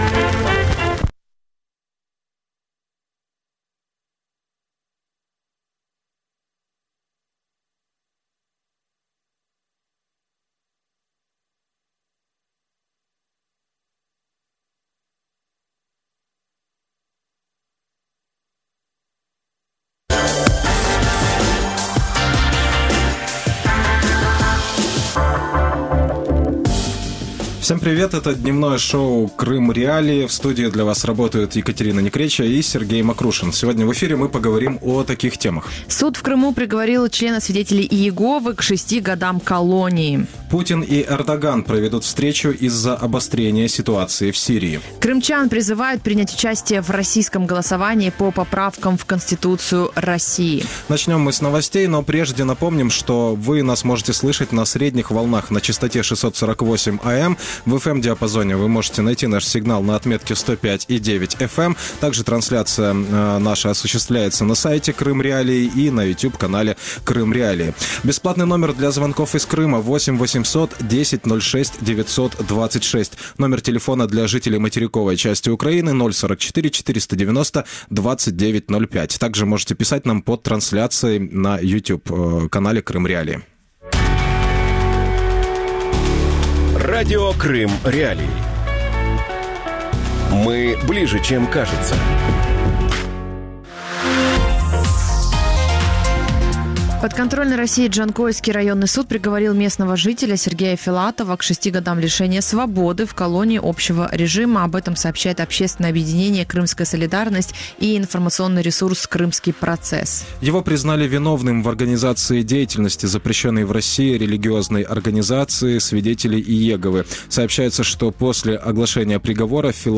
Поправки в Конституцию России и роль Крыма | Дневное ток-шоу